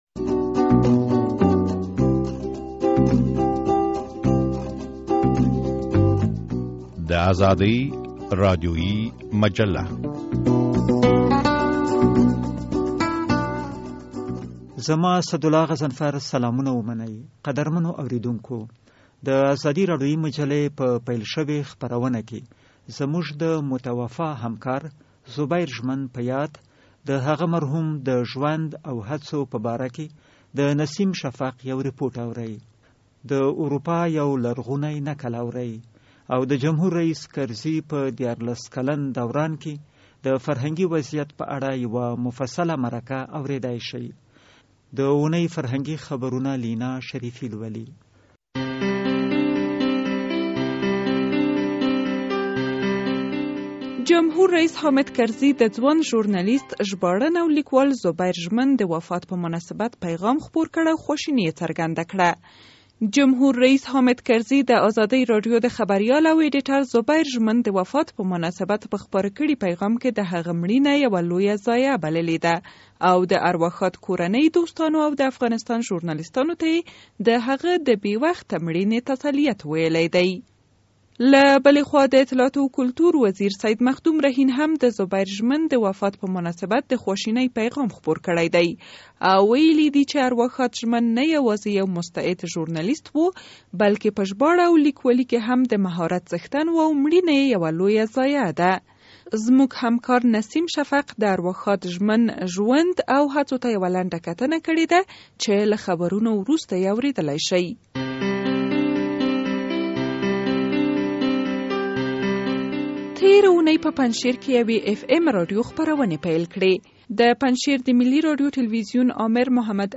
په روانه خپرونه کې د جمهور رییس حامد کرزي په تېر دیارلس کلن دوران کې د افغانستان د فرهنګي وضعیت په باره کې یو بحث اورئ.